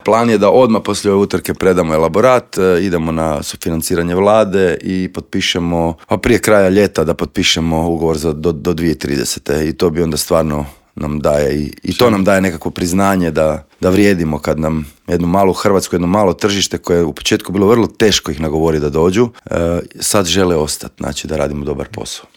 Osvrnuo se u intervjuu Media servisa na značaj utrke, utjecaj na gospodarstvo, stazu i na sve popratne događaje.